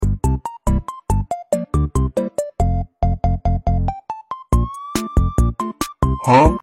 Cat Sound Effects Free Download